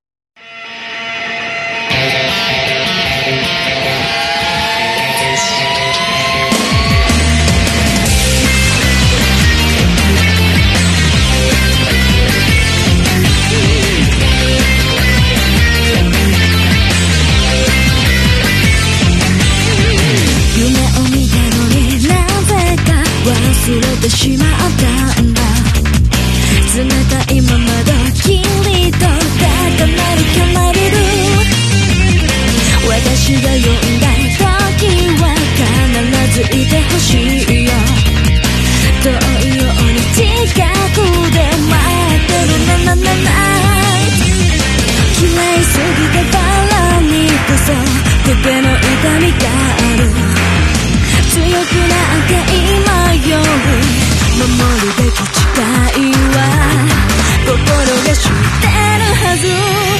Opening Creditless